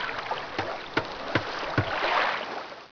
shipyard2.wav